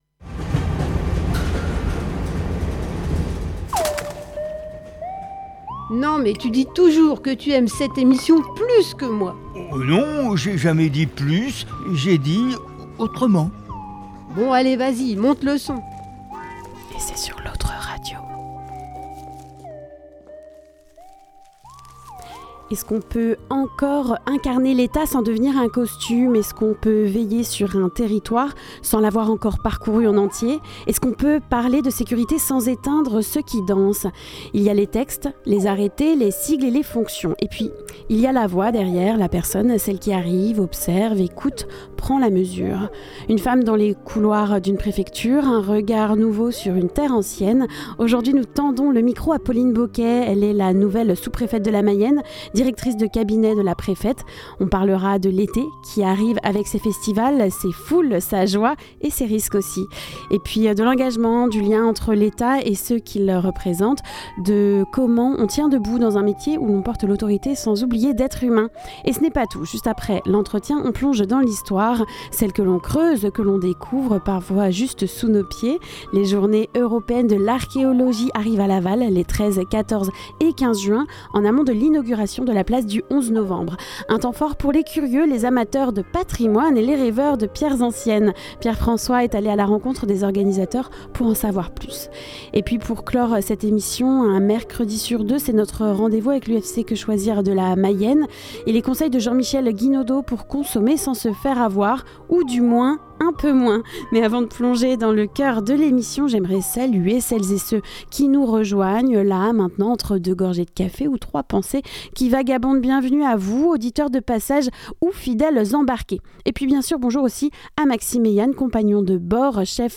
Rencontre avec Pauline Bocquet, nouvelle sous-préfète de la Mayenne
Dans notre émission du jour, nous recevons Pauline Bocquet, récemment nommée directrice de cabinet de la préfète de la Mayenne. L’occasion de faire connaissance avec cette haute fonctionnaire fraîchement arrivée sur le territoire, d’évoquer son parcours, sa mission, et les enjeux de sécurité en cette période estivale marquée par les festivals.